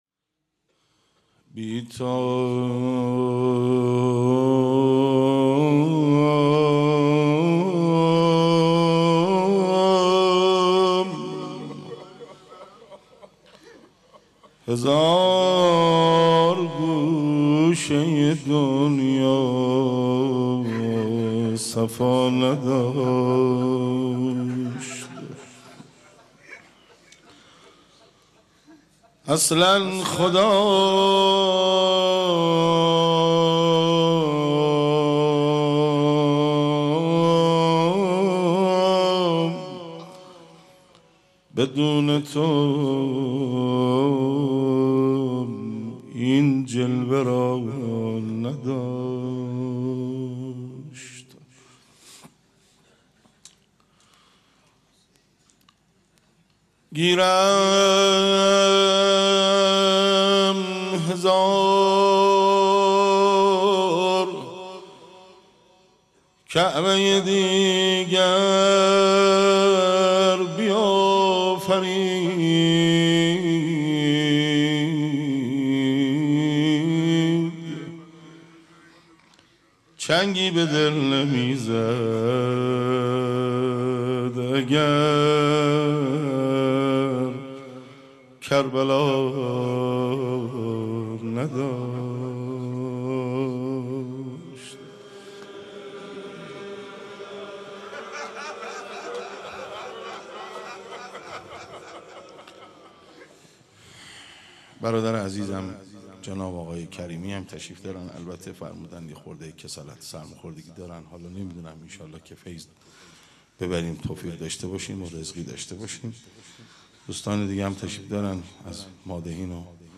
مناسبت : دهه دوم صفر
روضه